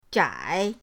zhai3.mp3